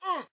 PlayerJump.ogg